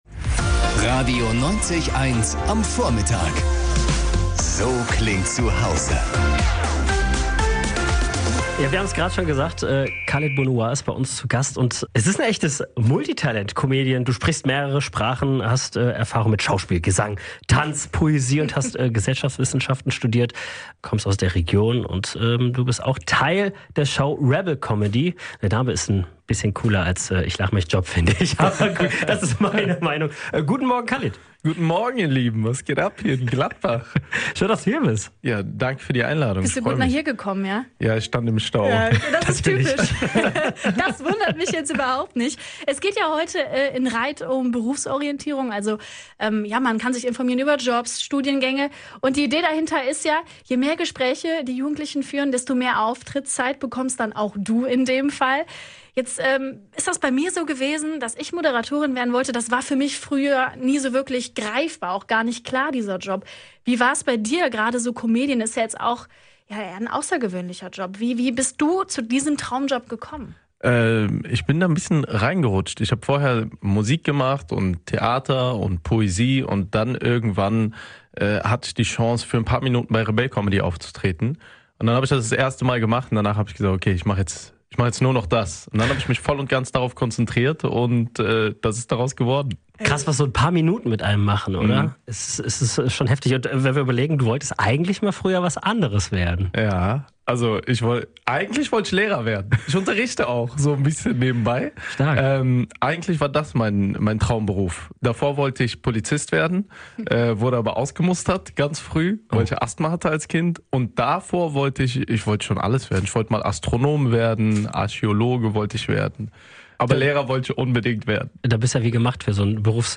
in der Radio 90,1 Morningshow mit Khalid über seine Jobs und seine Vergangenheit in Sachen Jobsuche gesprochen